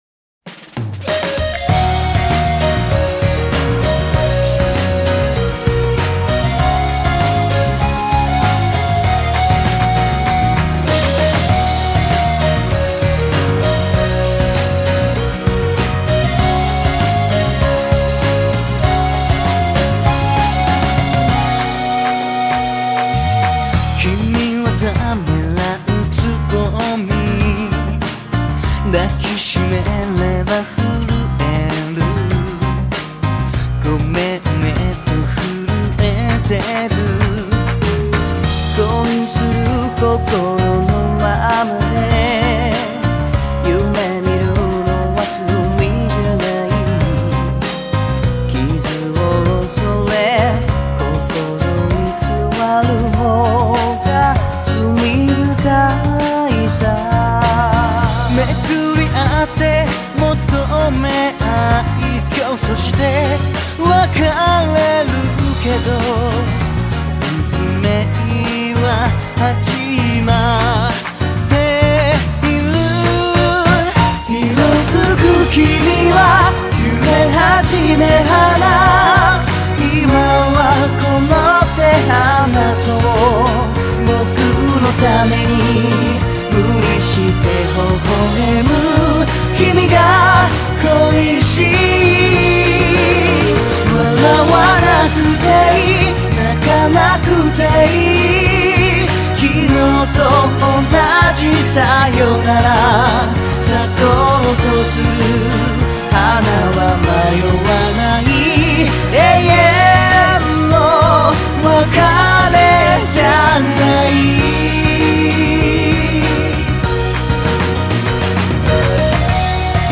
눈물나는 멜로디에, 살인적인 가사에...... 이건 나를 두 번 죽이는 일이야 TㅁT!!!